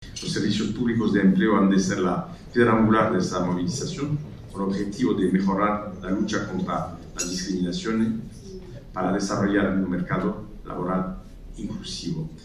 Embajadores, cónsules y consejeros de embajadas participaron en la Embajada de Francia en España en el VI Encuentro Diplomacia para la Inclusión organizado por el Grupo Social ONCE y la Academia de la Diplomacia, bajo el patrocinio del embajador francés en nuestro país, que ostenta la presidencia semestral del Consejo de la UE.